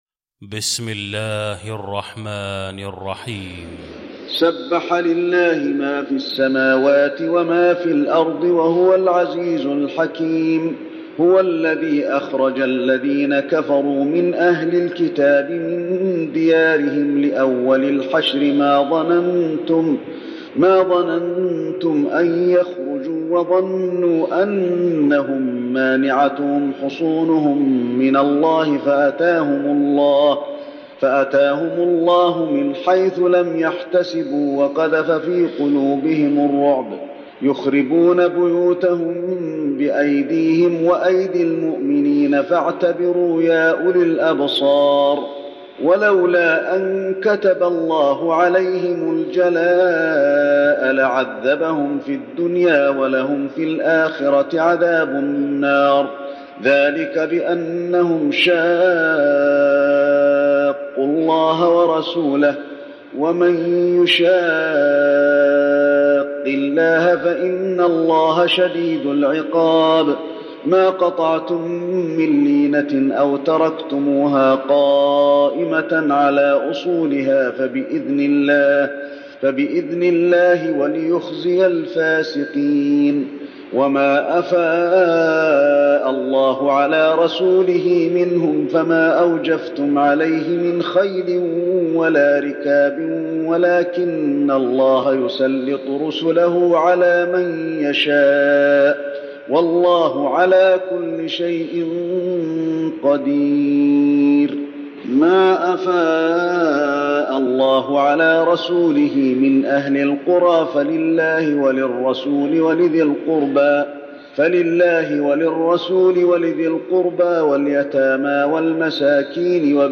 المكان: المسجد النبوي الحشر